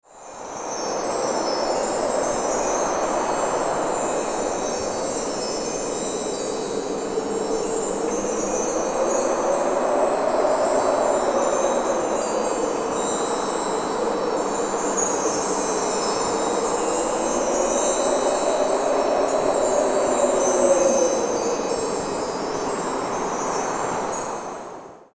Christmas Chimes And Wind Sound Effect
Description: Christmas chimes and wind sound Effect. Perfect sound for creating a magical Christmas atmosphere. Santa sleigh noise.
Christmas-chimes-and-wind-sound-effect.mp3